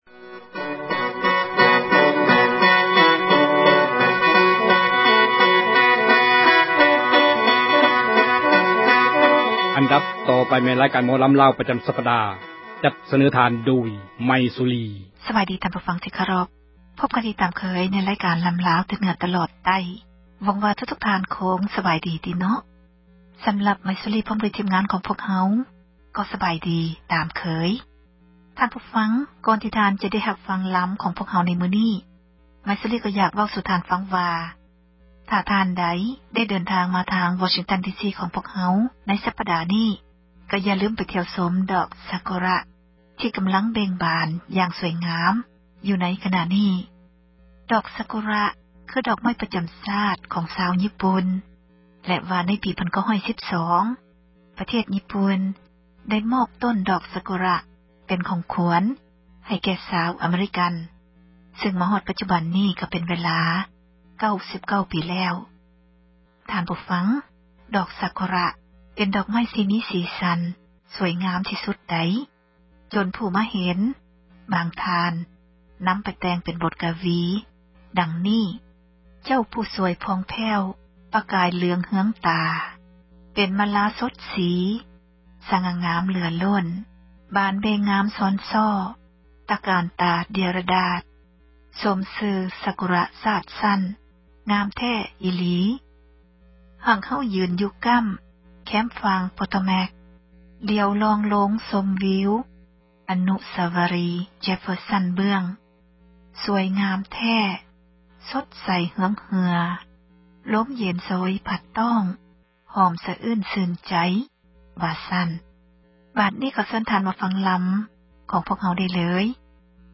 ຣາຍການ ໝໍລໍາ ແຕ່ເໜືອ ຕລອດໃຕ້ ຈັດມາສເນີທ່ານ